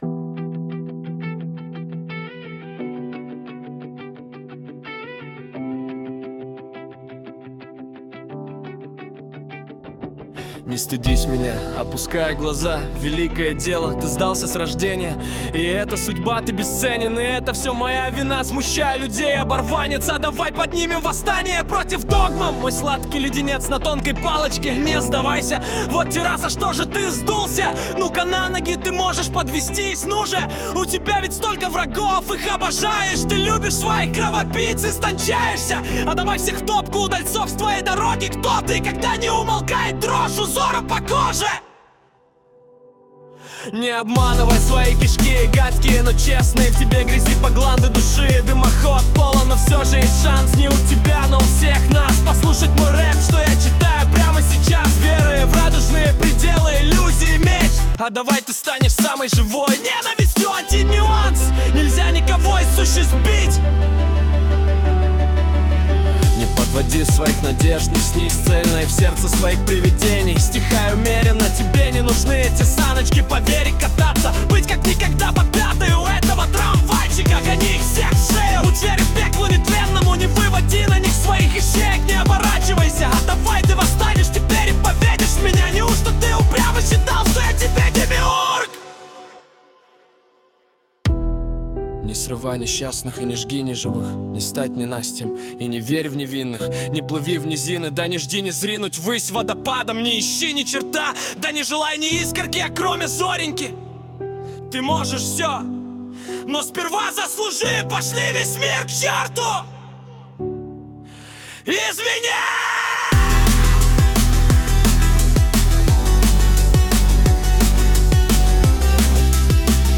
Музыка и исполнение принадлежит ИИ.
ТИП: Пісня
СТИЛЬОВІ ЖАНРИ: Драматичний